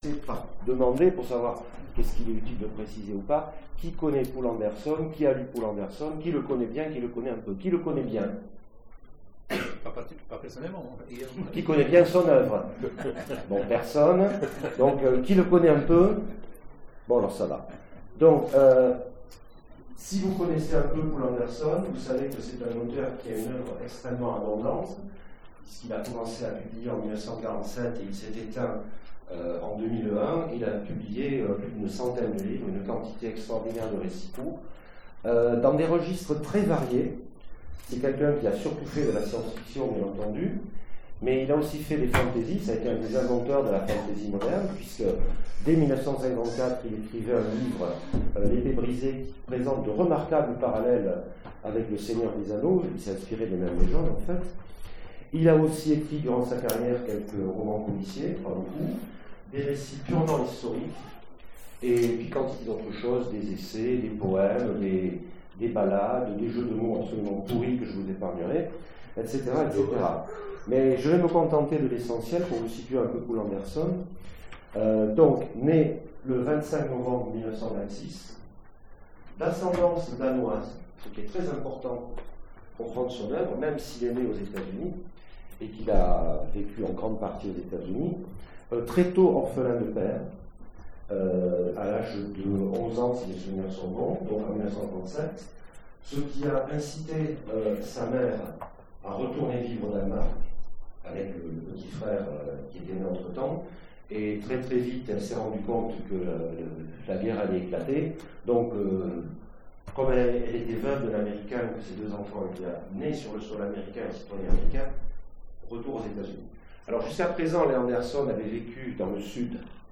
Il manque les premières secondes du cours, nos excuses.
Conférence